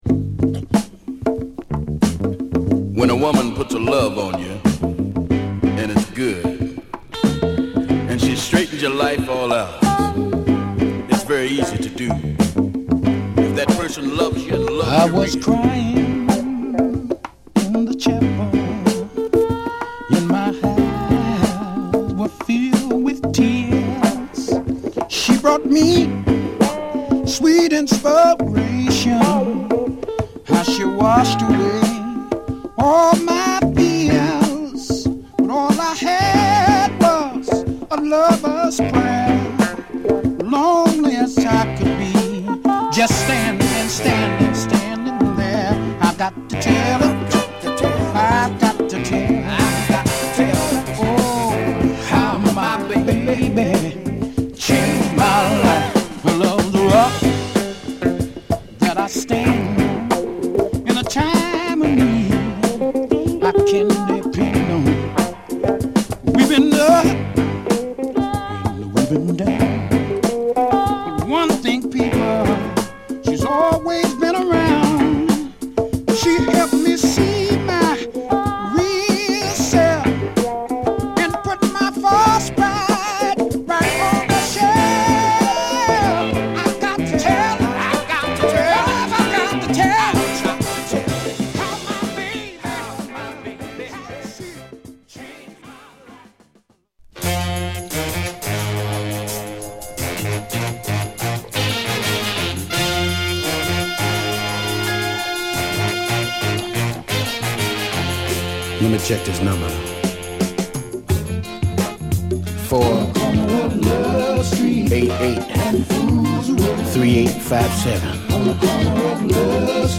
パーカッションが際立つイントロブレイクから、ホッコリとした緩いメロディでジワジワと高揚していくナイスなミディアム！